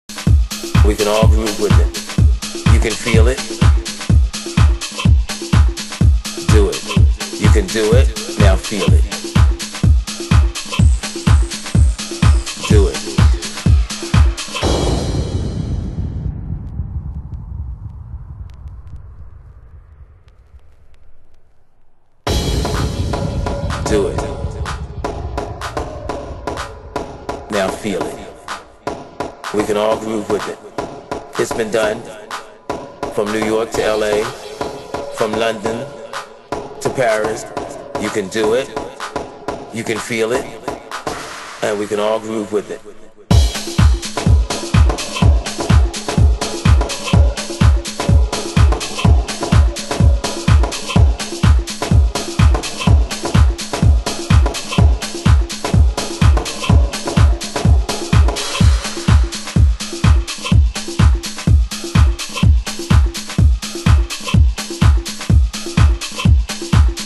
(Beatapella) 　　盤質：少しチリパチノイズ有